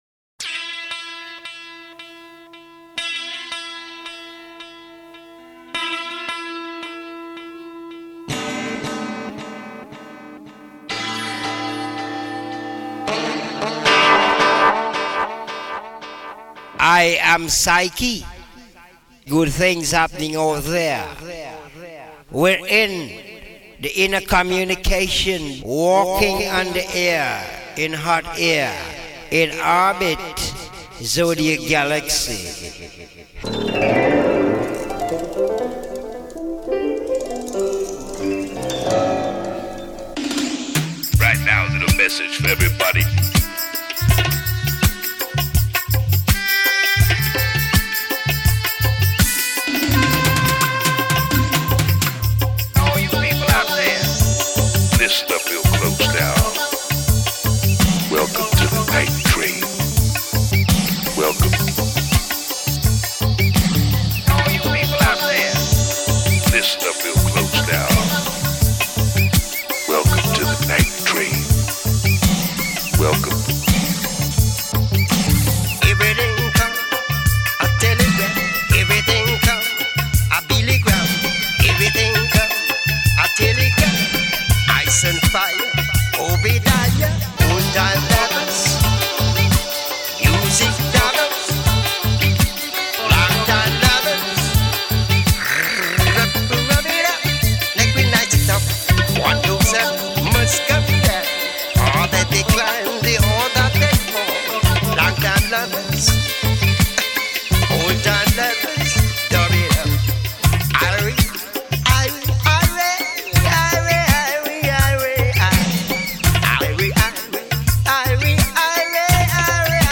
Recorded at Berry Street studio